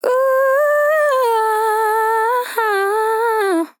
Categories: Vocals Tags: dry, english, Feel, female, fill, LOFI VIBES, OH, sample